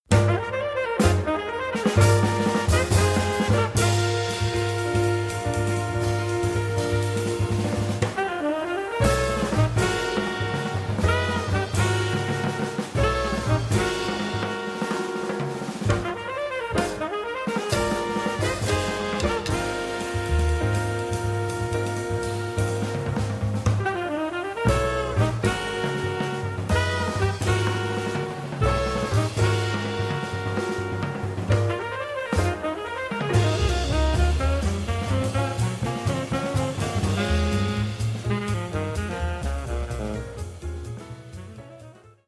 tenor saxophonist